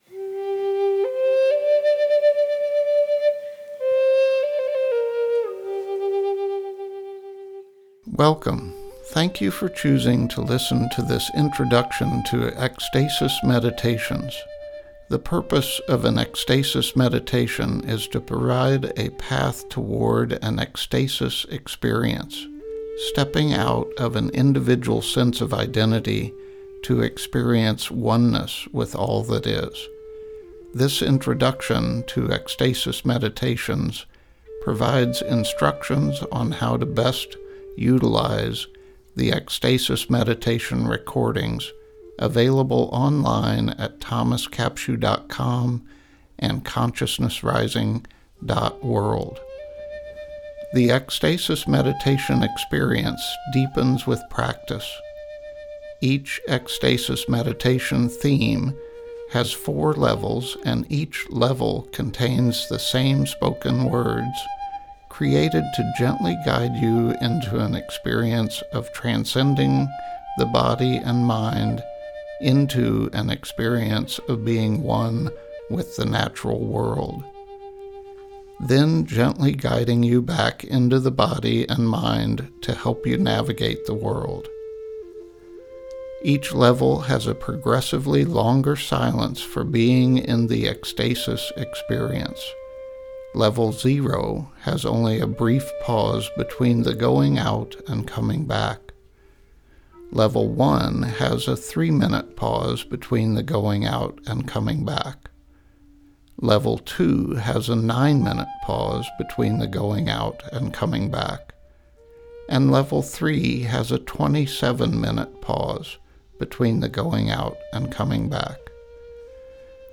Meditations
soothing voice and theta brain wave music